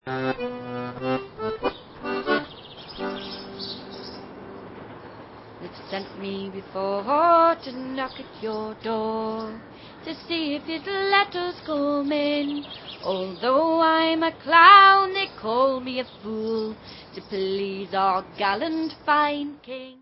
sledovat novinky v oddělení Folk